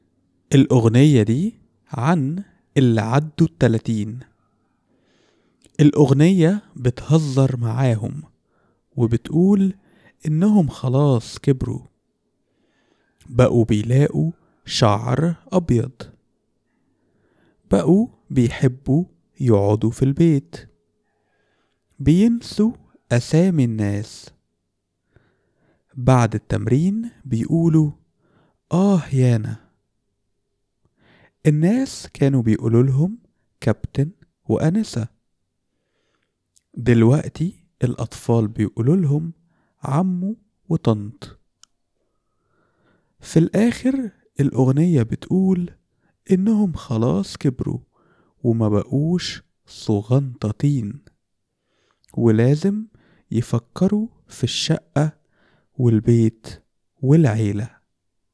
It’s a light-hearted song speaking to people over 30.